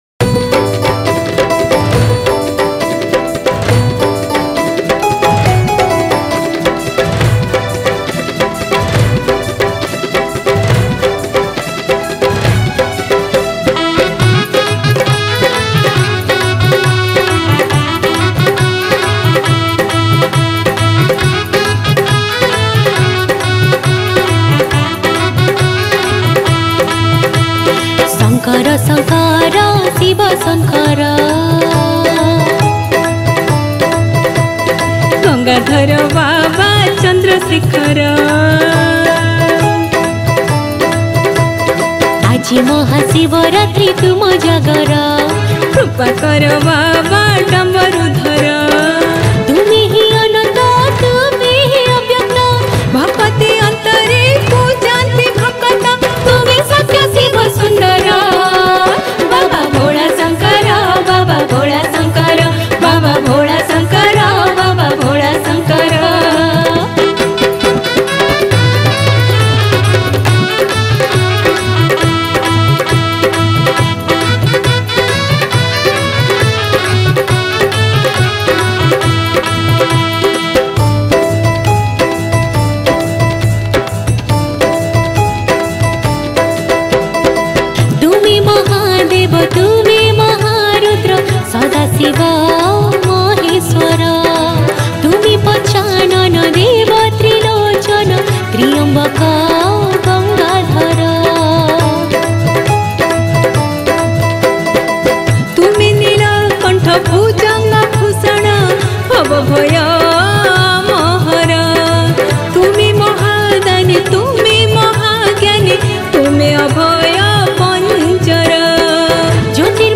Jagara Special Odia Bhajan Song Upto 2021 Songs Download